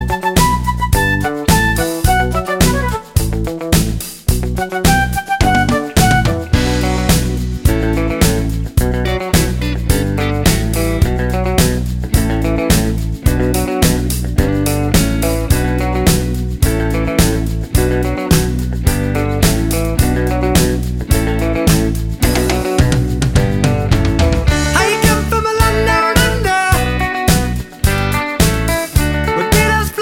No Guitars Pop (1980s) 3:16 Buy £1.50